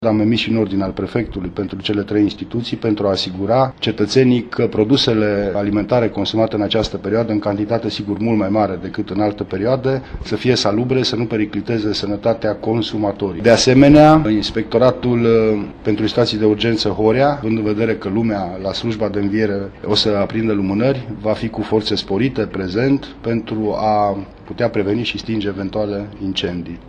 Prefectul județului Mureș, Lucian Goga: